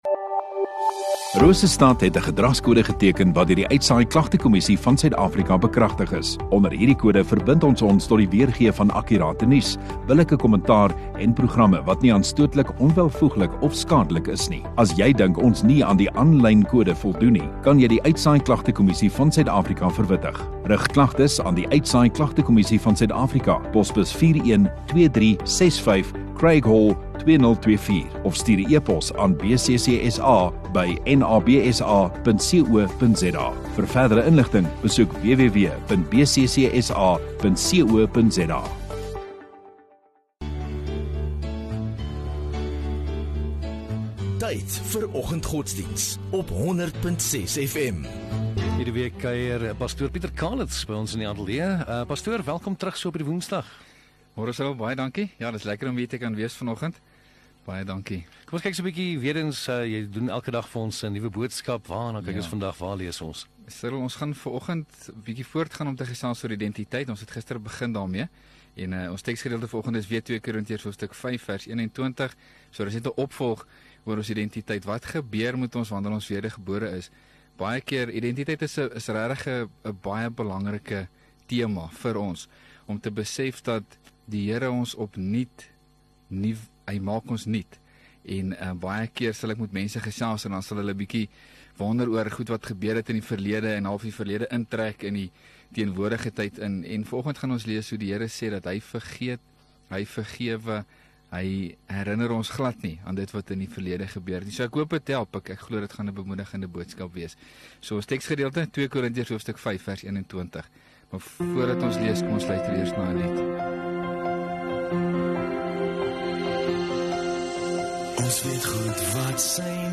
6 Mar Woensdag Oggenddiens